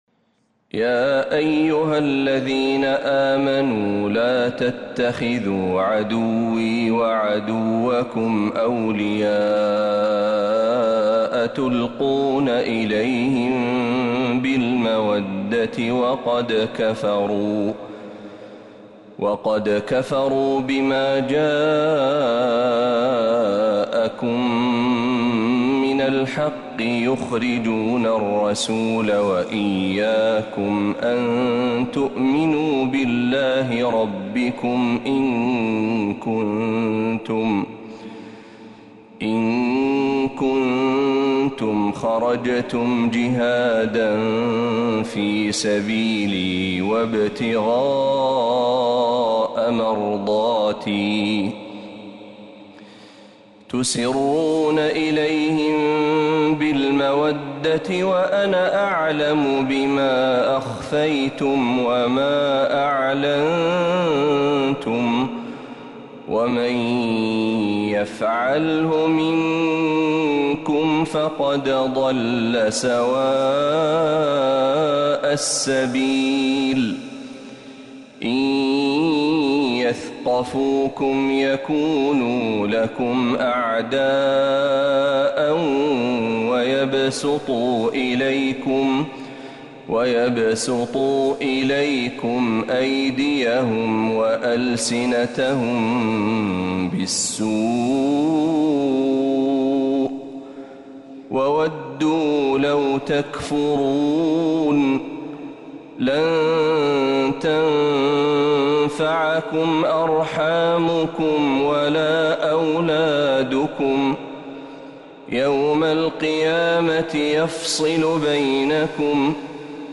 سورة الممتحنة كاملة من الحرم النبوي